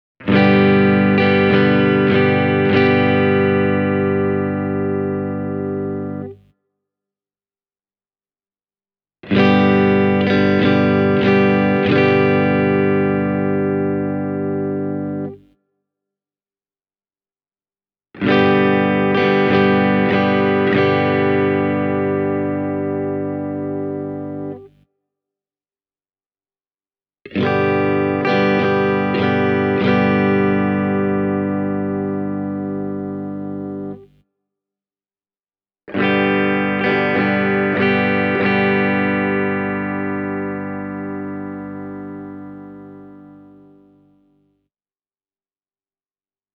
The guitar’s three pickups, five-way switch, and humbucker split gives you a very cool selection of different Gibson- and Fender-style tones.
Thanks to the moderate output of the humbuckers, there’s never any danger of the sound getting mushy or ill-defined.
Here we have a similar clip featuring the full humbuckers:
vuorensaku-t-style-clean-e28093-full-humbuckers.mp3